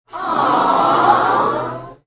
Звук восхищения ооо